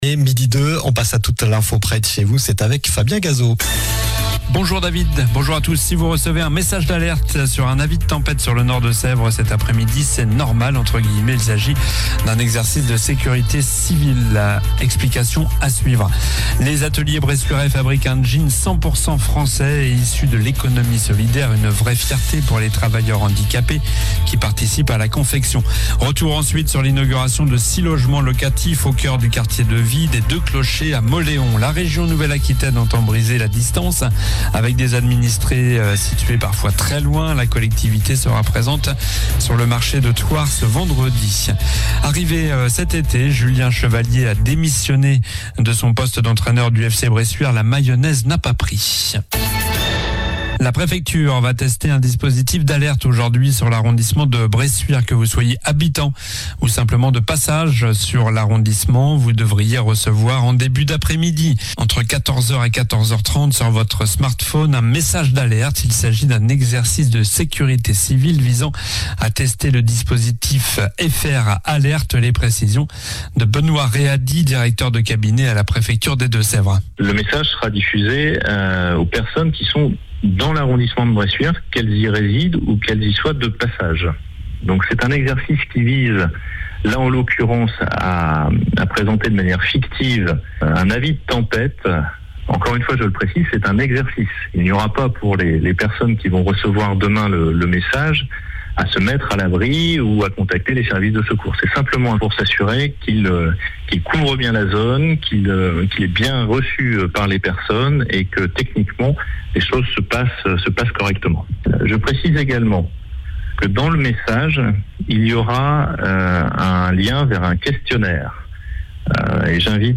Journal du mercredi 18 septembre (midi)